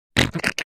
snort.wav